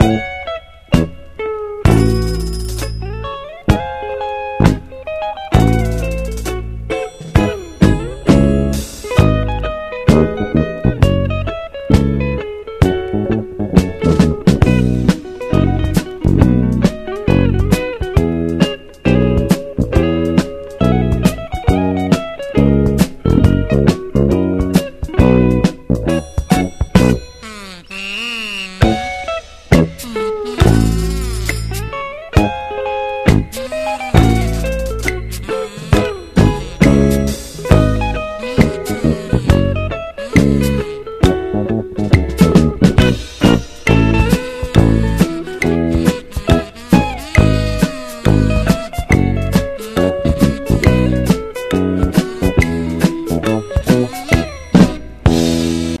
IDOL / JAPANESE CLUB
幅広いクリエイターがバックアップし、HIP HOP、EDMも前のめり取り込み進化した2ND！